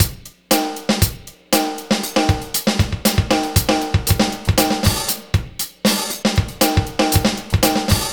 Gliss 2fer 5 Drumz.wav